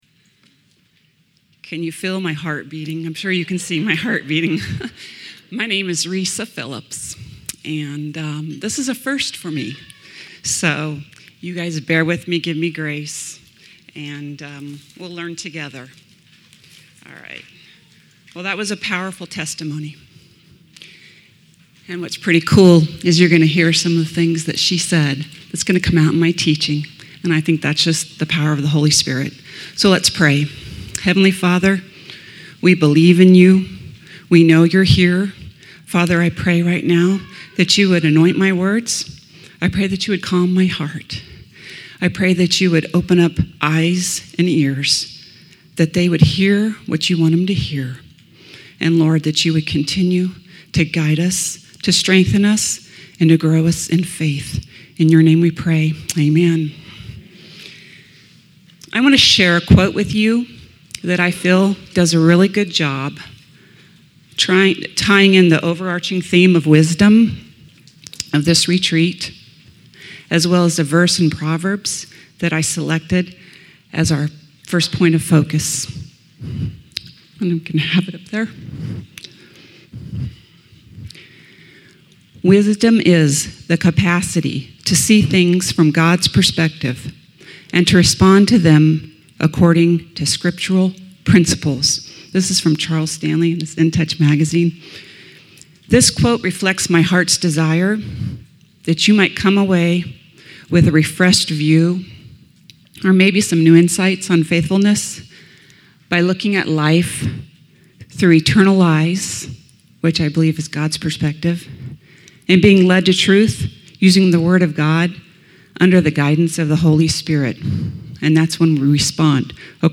at the 2015 Women's Retreat: Pearl's of Wisdom